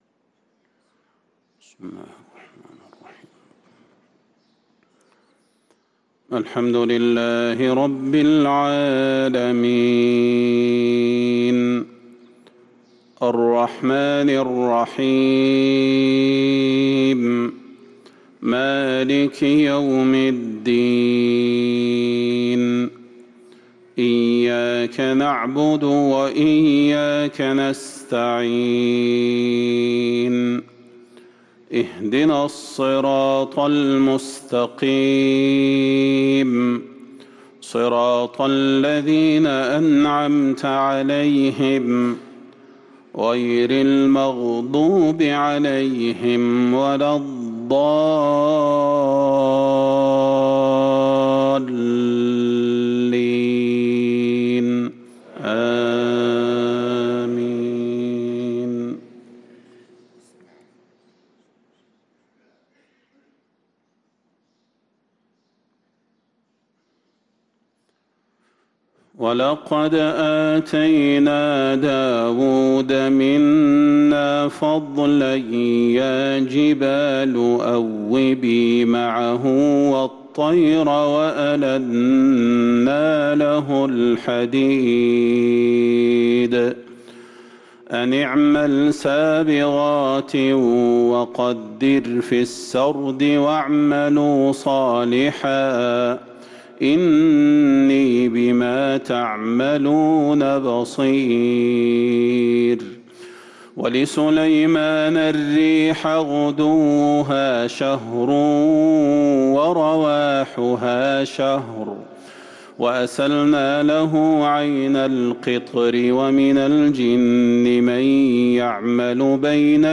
صلاة الفجر للقارئ صلاح البدير 7 جمادي الآخر 1444 هـ